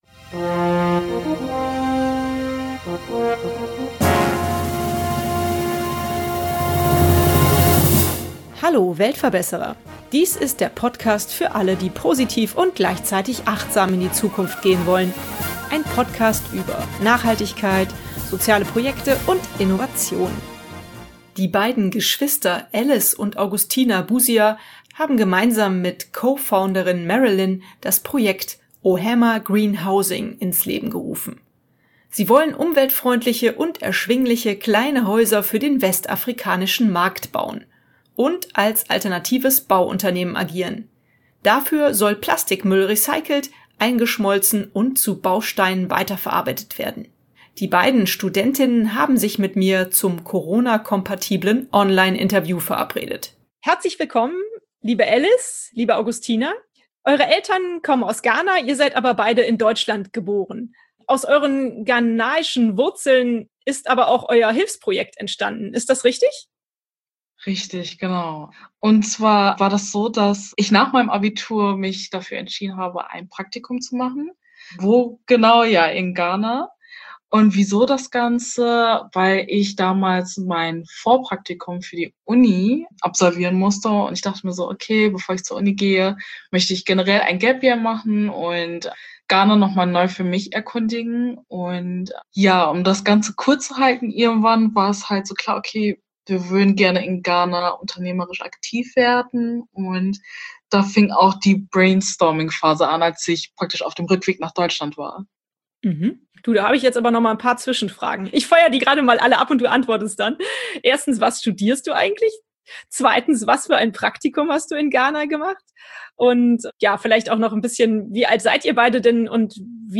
Sie wollen umweltfreundliche und erschwingliche kleine Häuser für den westafrikanischen Markt bauen und als alternatives Bauunternehmen agieren. Dafür soll Plastikmüll recyclt, eingeschmolzen und zu Bausteinen weiterverarbeitet werden. Die beiden Studentinnen haben sich mit mir zum Corona-kompatiblen Online-Interview verabredet: Mehr